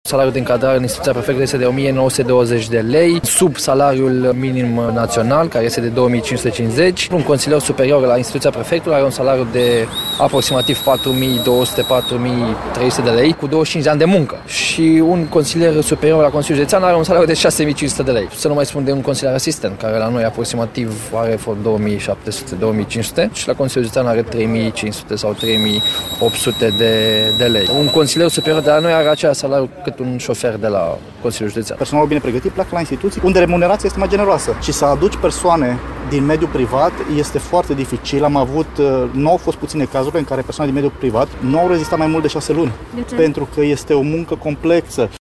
Angajații din Prefectura Timiș au întrerupt astăzi munca timp de o oră și au ieșit în fața Palatului Administrativ, în semn de protest față de salariile mici pe care le încasează.
09-Vox.mp3